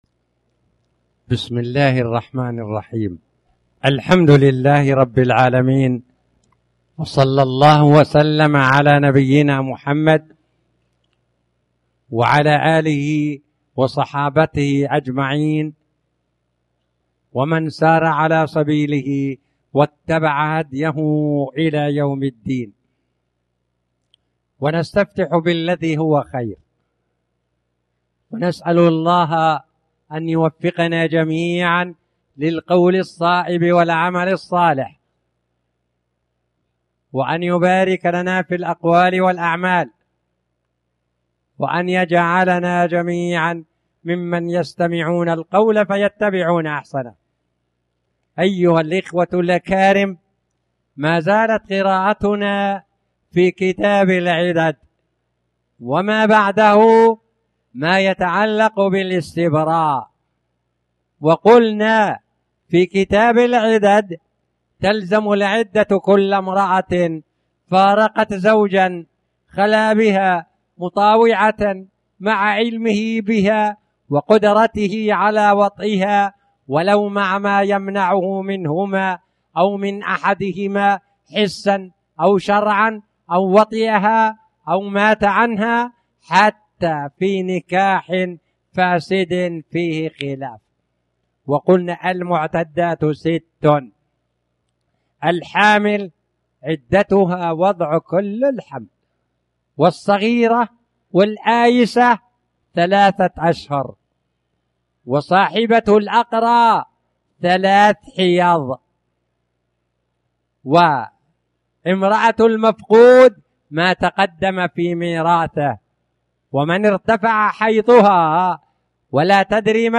تاريخ النشر ١١ صفر ١٤٣٩ هـ المكان: المسجد الحرام الشيخ